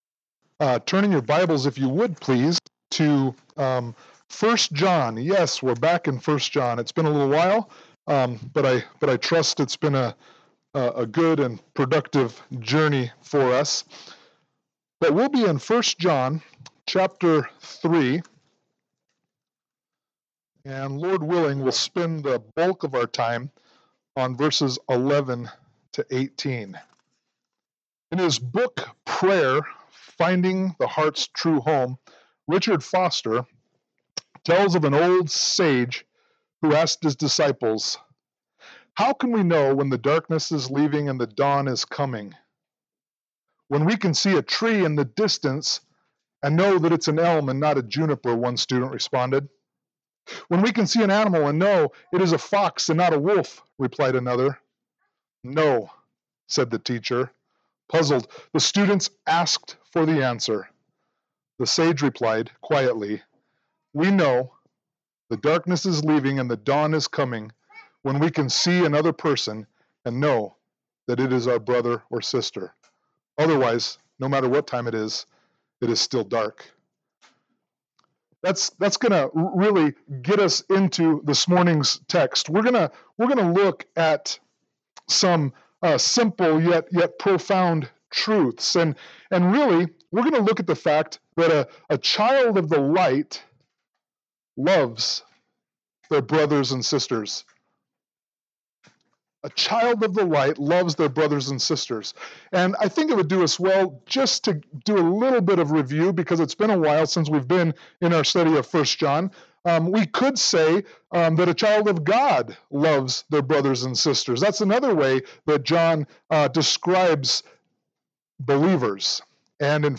1 John 3:11-18 Service Type: Sunday Morning Worship « Vision 2022-2023 Philippians 2:1-11